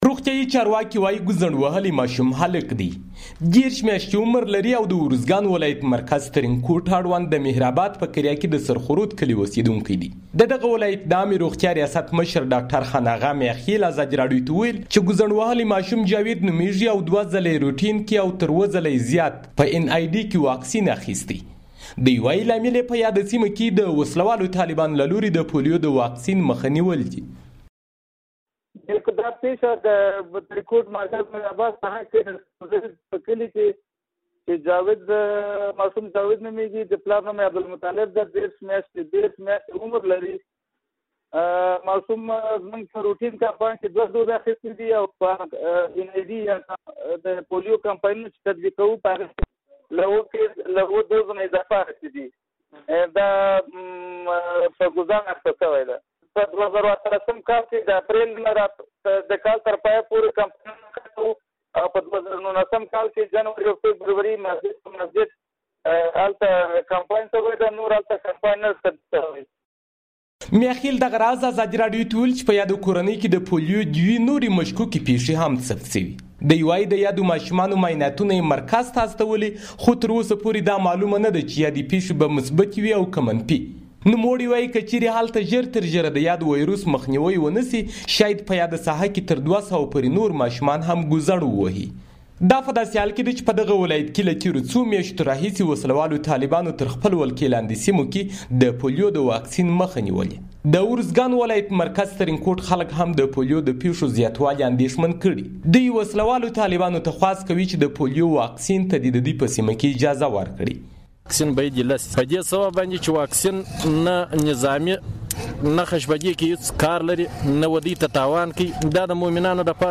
د ارزګان راپور